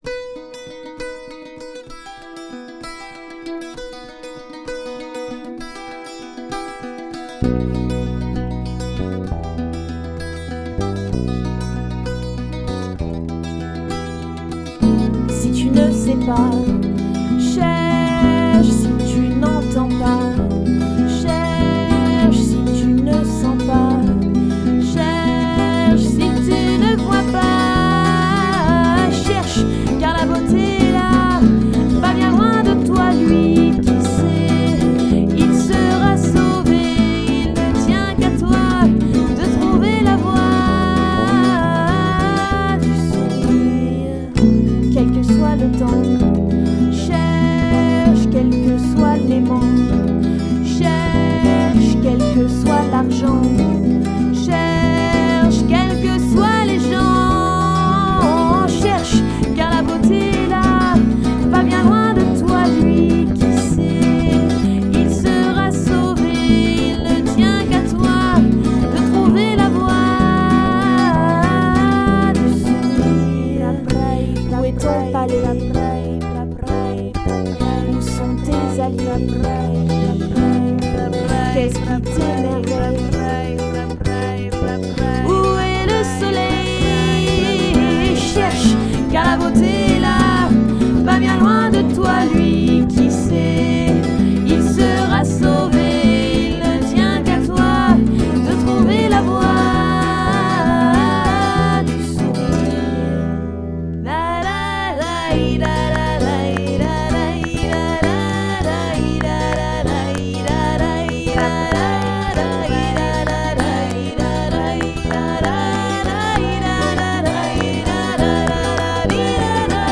chant, guitare, bruitages
guitares, laud
guitare basse, percussions